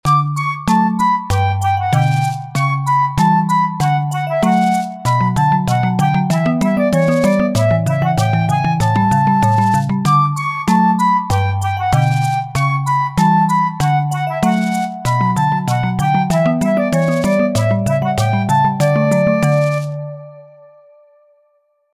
Children's Theme, Theater Music